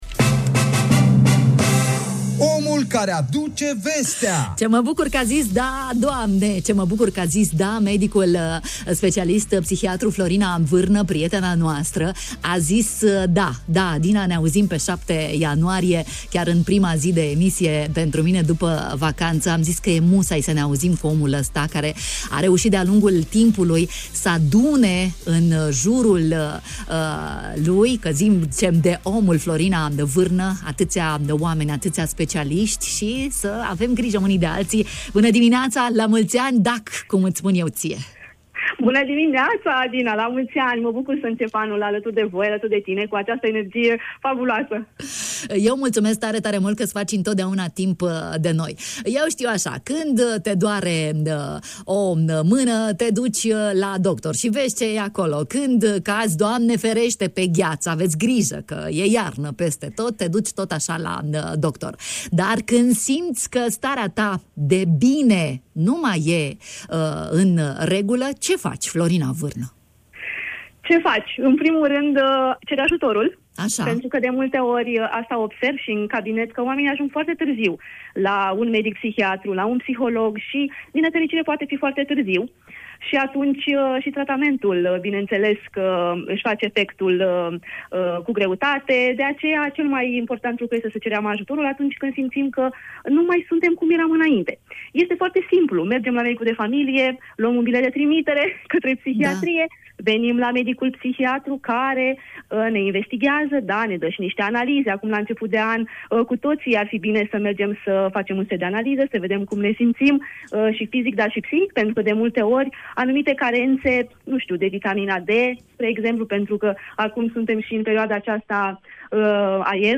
Radio Iaşi